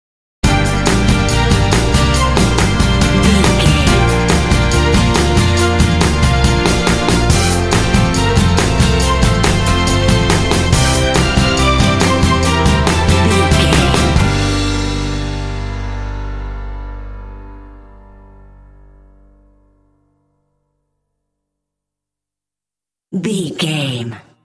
Fast paced
In-crescendo
Dorian
Fast
energetic
cheerful/happy
strings
bass guitar
drums
synthesiser
piano
symphonic rock
cinematic